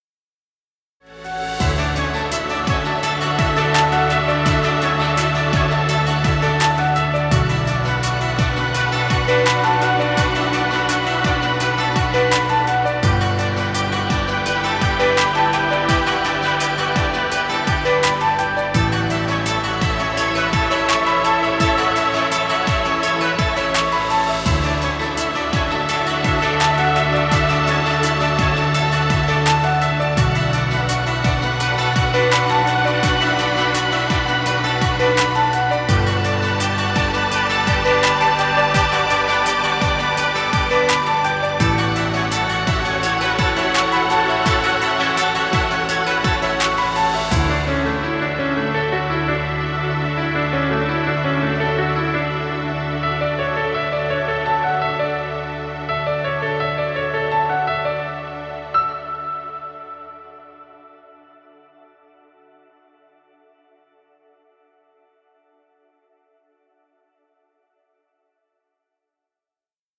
Chillout music. Background music Royalty Free.